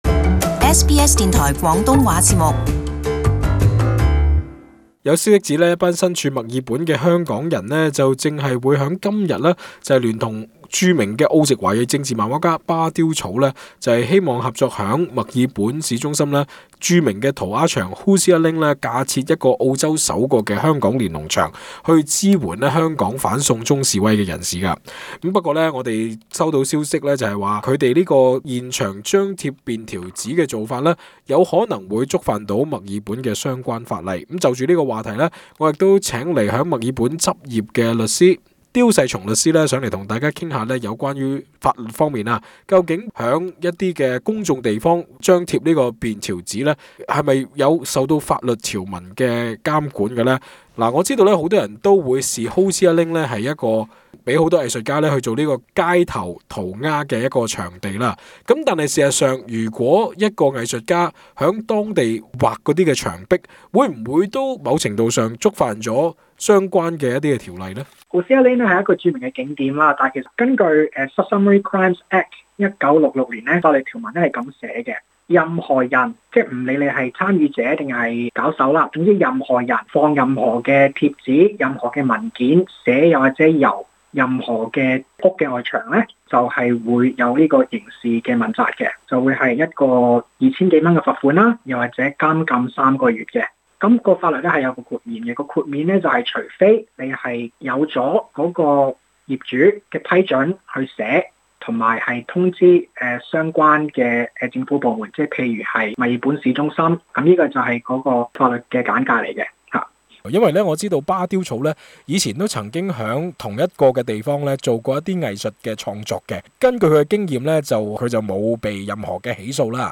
【時事專訪】墨爾本辦「連儂牆」或觸犯維州法例？